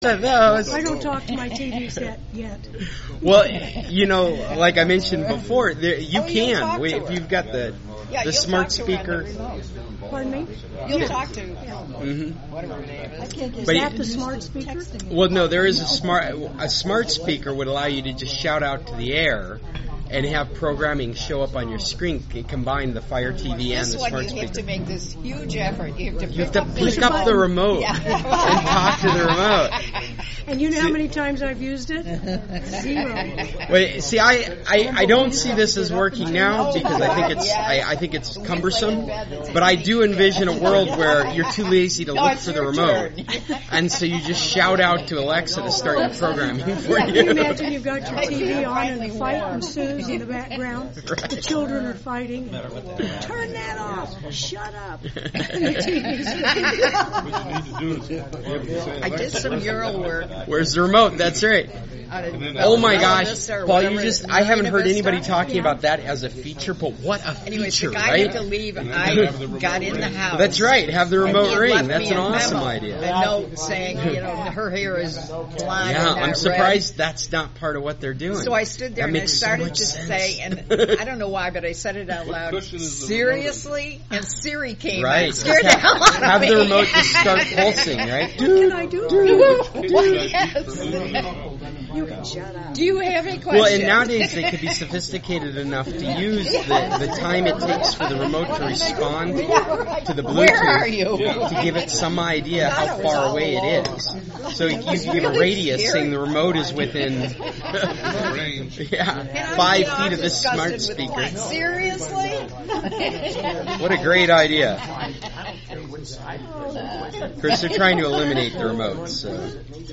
If you missed our meeting in real-time, then you can always listen to this podcast.